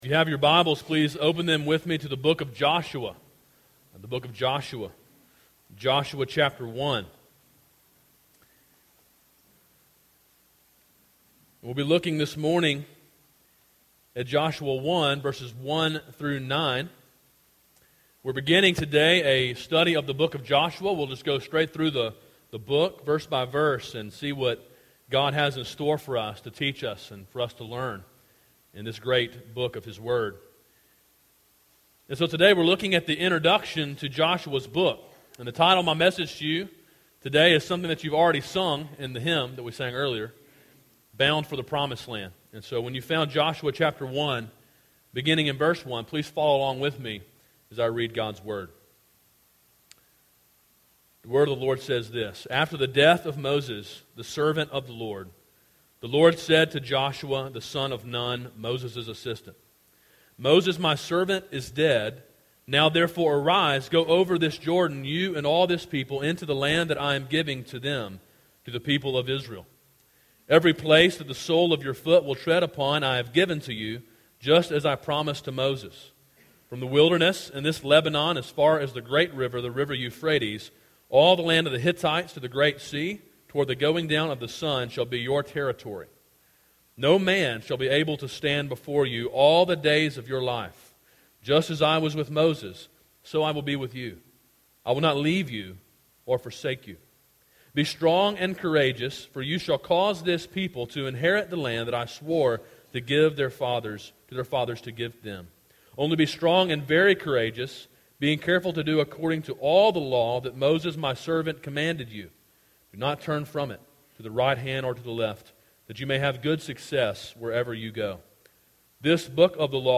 A sermon in a series on the book of Joshua.